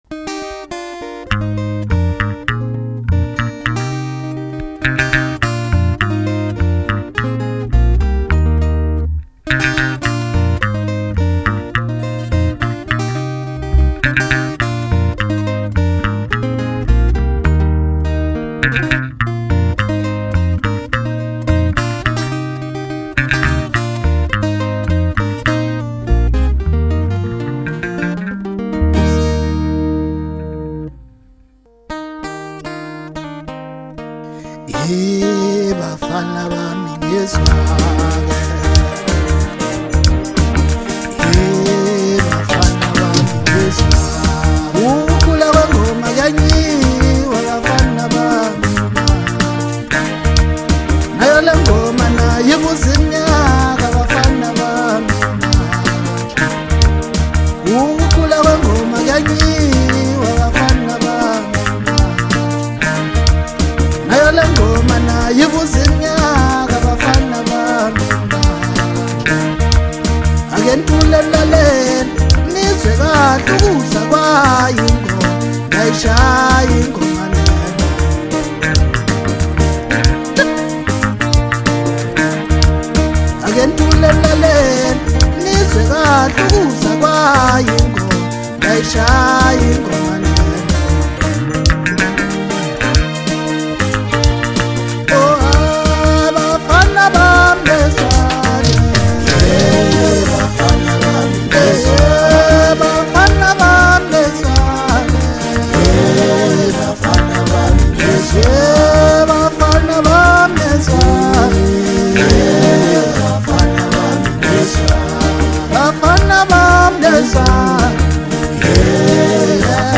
• Genre: Makandi